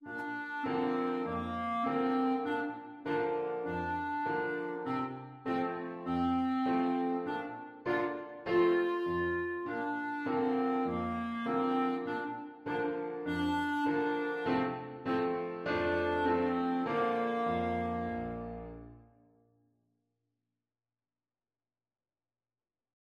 4/4 (View more 4/4 Music)
Allegretto